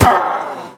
Minecraft Version Minecraft Version snapshot Latest Release | Latest Snapshot snapshot / assets / minecraft / sounds / entity / witch / death2.ogg Compare With Compare With Latest Release | Latest Snapshot